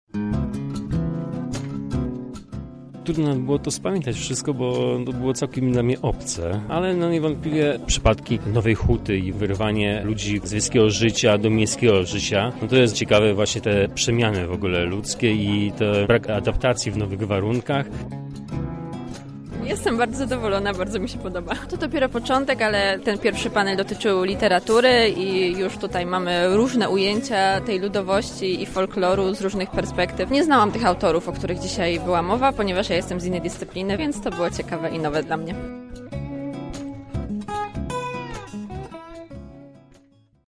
Zapytaliśmy uczestników co najbardziej zapadło im w pamięć: